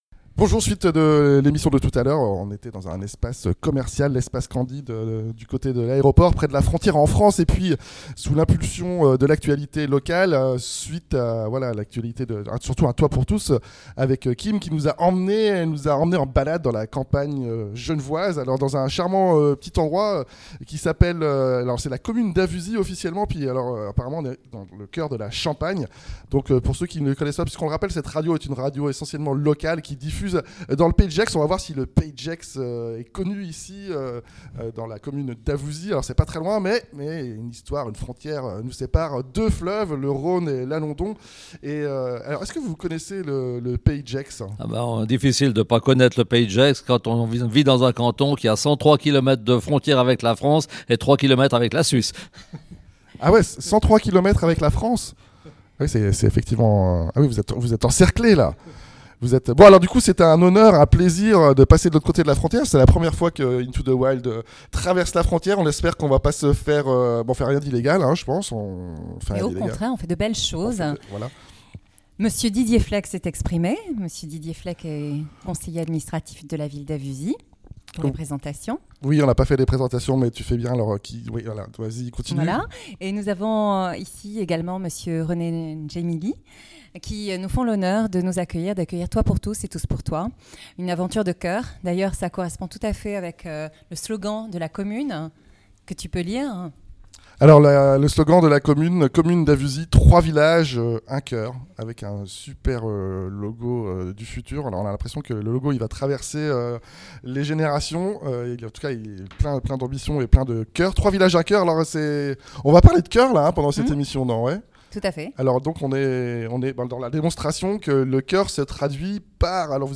Interview du maire et de son adjoint de la commune d'Avusy, s'associant avec Toit Pour Tous pour réaliser des hebergements d'acceuil, du coeur à tous les étages.